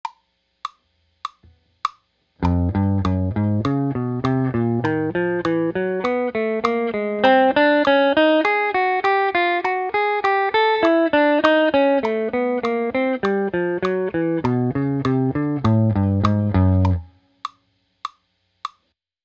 Ex-009 Fretting Combinations Chromatic Exercise Set 2